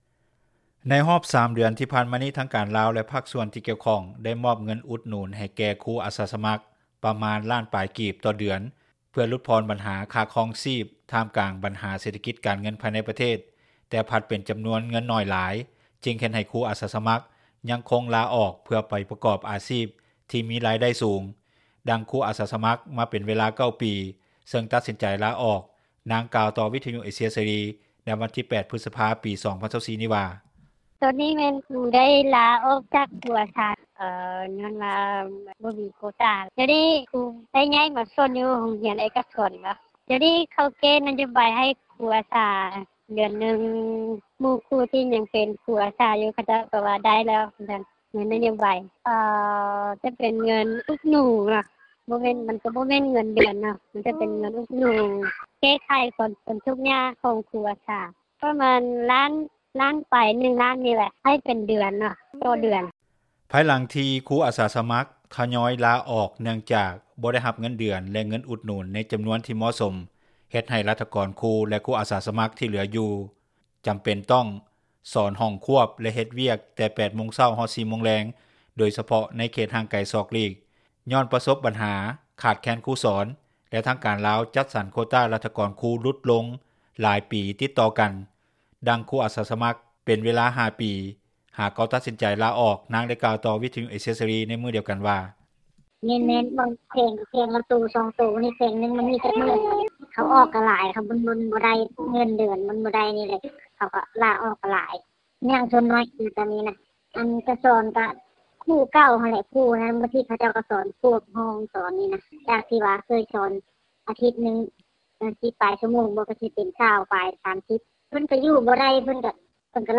ດັ່ງຄູອາສາສະມັກ ເປັນເວລາ 5 ປີ ໄດ້ຕັດສິນໃຈລາອອກ ນາງໄດ້ກ່າວຕໍ່ວິທຍຸເອເຊັຽເສຣີ ໃນມື້່ດຽວກັນວ່າ:
ດັ່ງເຈົ້າໜ້າທີ່ ຫ້ອງການສຶກສາທິການ ຜະແນກການເງິນ-ການບັນຊີ ແຂວງພາກເໜືອນາງກ່າວວ່າ:
ດັ່ງເຈົ້າໜ້າທີ່ ກະຊວງສຶກສາທິການ ແລະກິລາກ່າວວ່າ: